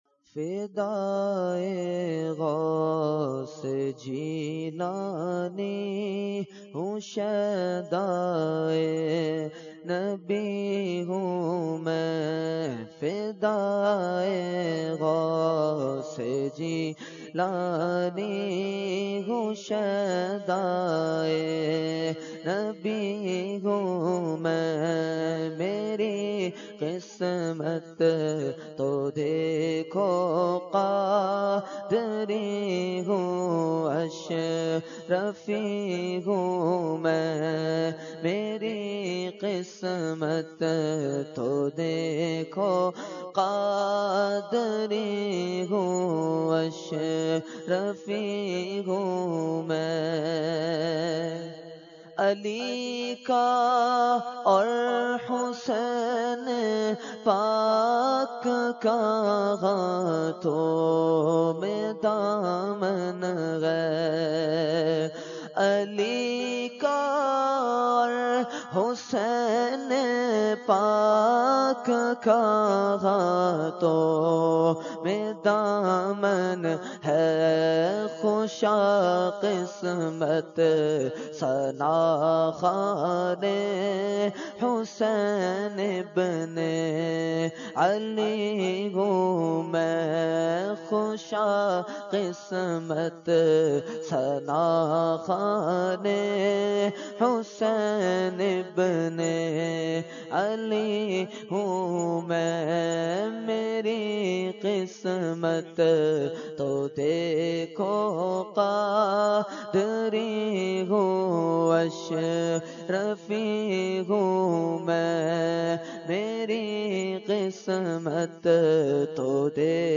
Category : Manqabat | Language : UrduEvent : Urs Makhdoome Samnani 2016